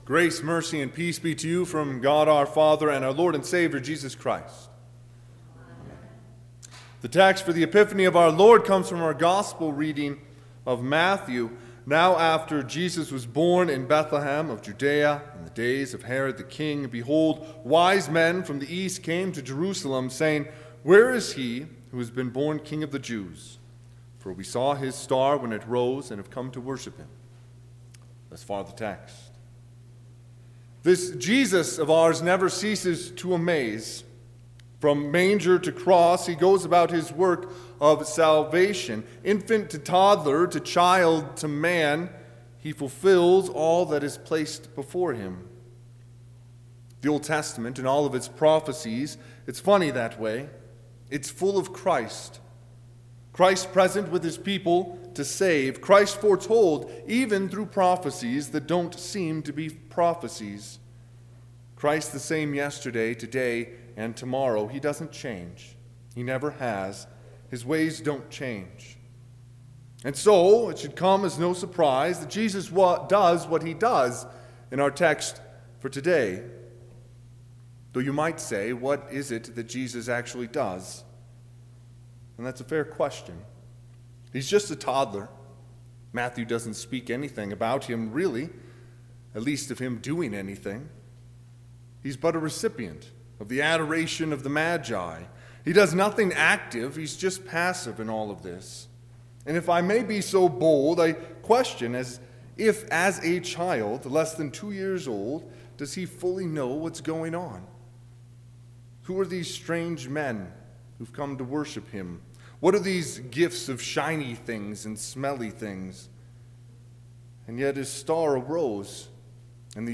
Sermon – 1/6/2020
Sermon_Jan6_2020.mp3